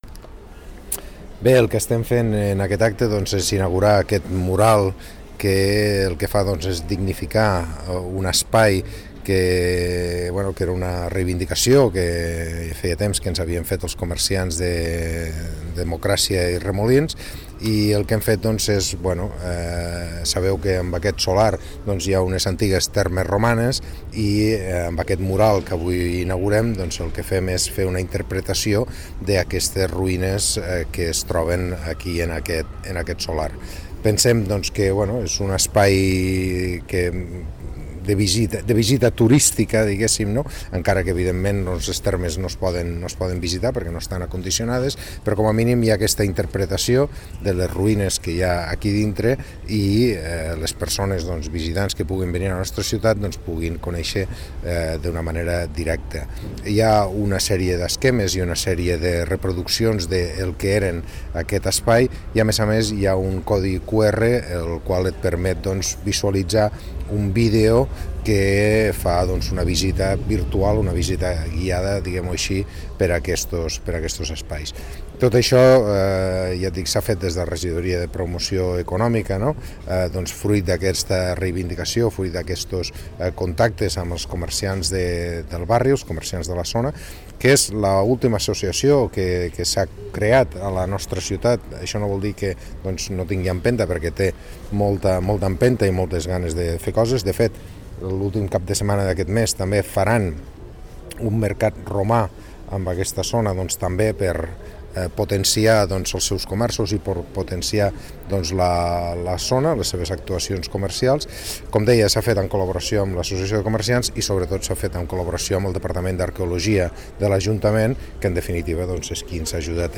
Tall de veu R.Peris L’actuació, que ha costat 4.200 euros, dóna resposta a la sol·licitud de l’Associació de Comerciants de Democràcia i Remolins per visibilitzar les restes romanes que hi ha al solar.